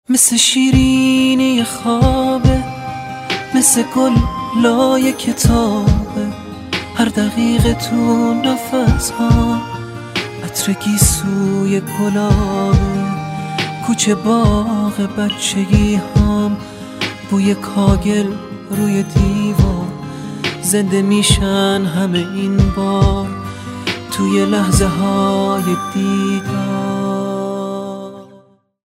رینگتون احساسی و با کلام